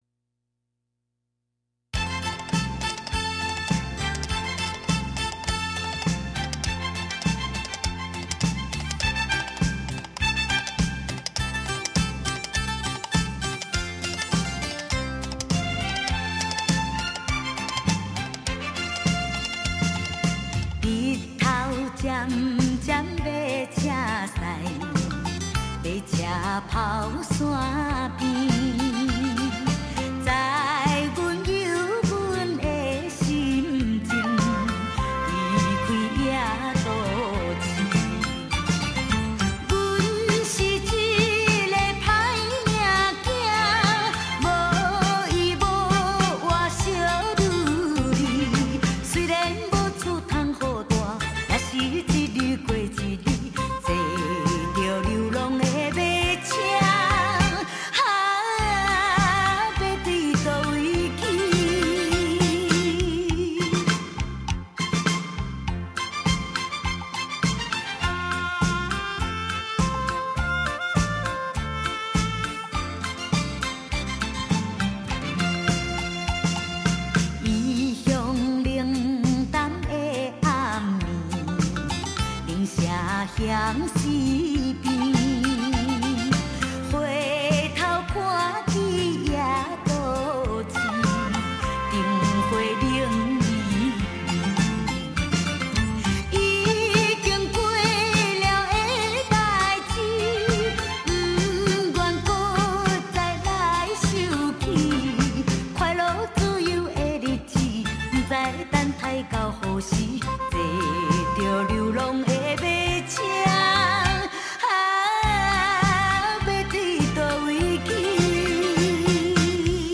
旋律简单，很容易让人朗朗上口。